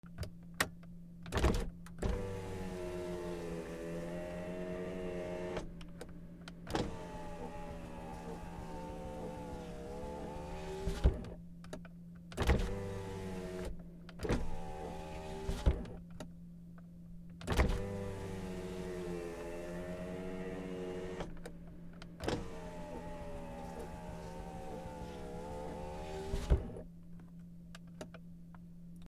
車 窓の開け閉め
/ E｜乗り物 / E-10 ｜自動車 / ファンカーゴ
H4n